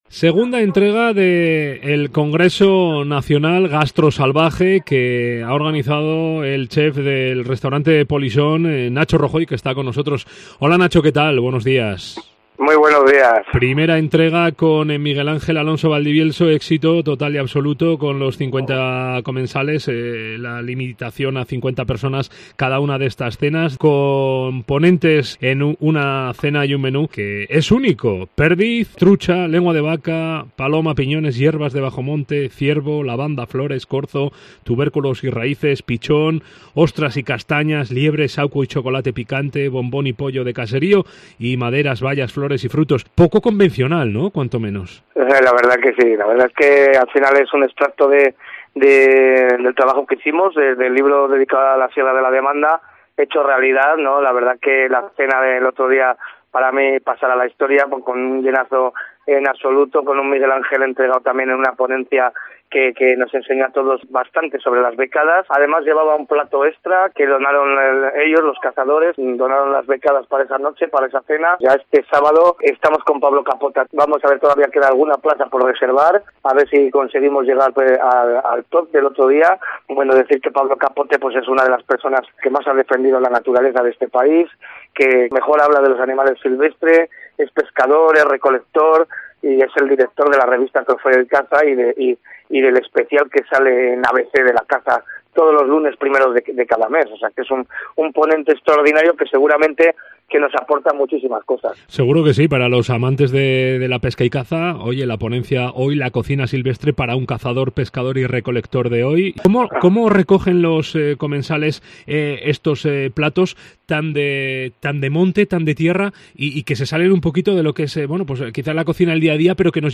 I CONGRESO GASTROSALVAJE. Entrevista